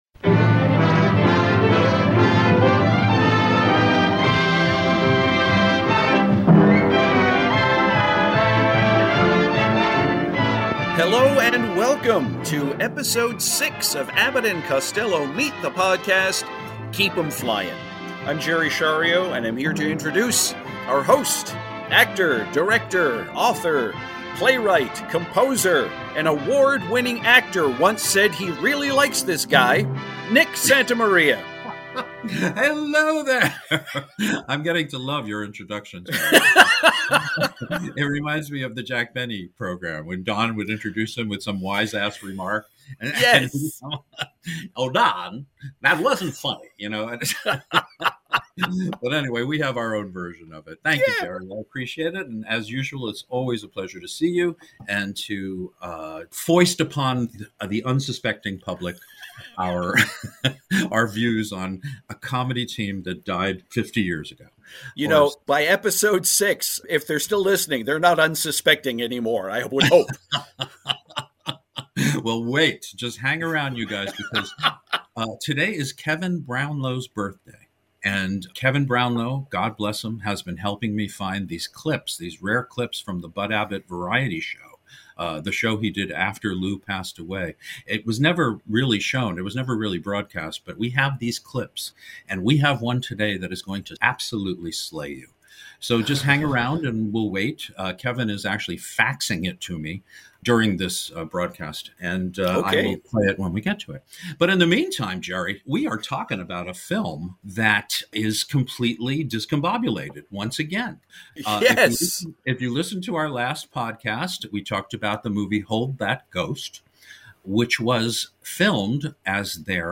You'll hear the backstory regarding the film being shoved ahead of RIDE 'EM COWBOY to satisfy a nation getting ready for war. Also hear the final clip from the ultra rare Bud Abbott Variety Show, where he confronts a VERY special guest.